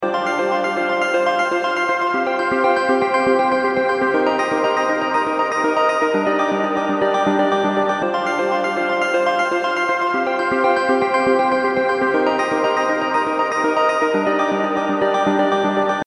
120 BPM Electronic Synth Loops & Melodic Sequences
Professional electronic synth loops featuring plucky textures and rhythmic sequences.
Genres: Synth Loops
Tempo: 120 bpm
120-bpm-electronic-synth-loops-melodic-sequences.mp3